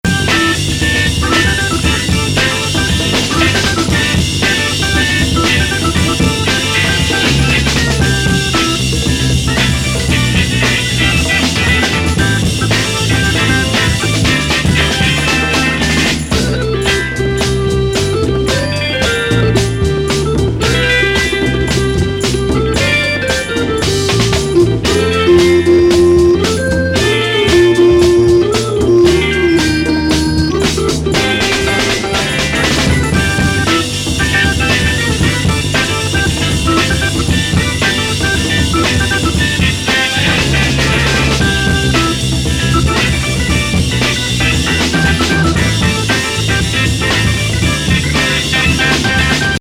ファンク大爆発!!ドラミングにオルガン・ベロベロ!!